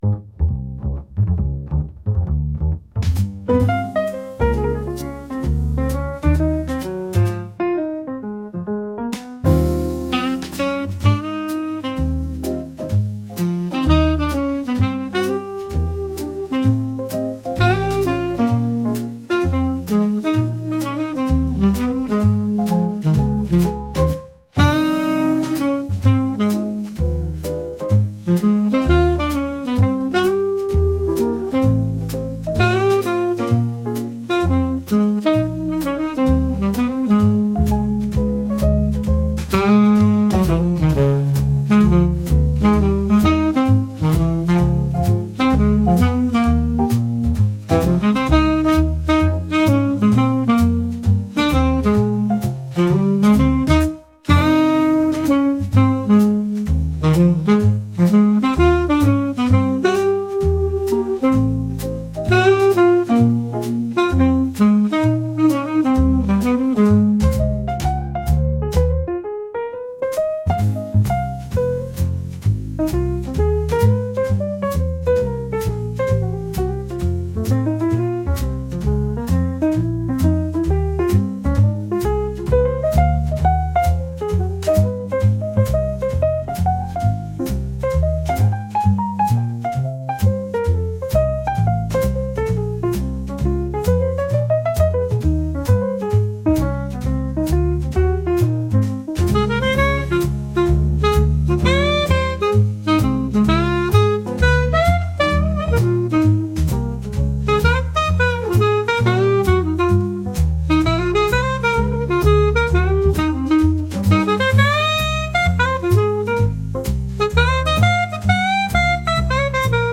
落ち着いた感じのジャズ曲です。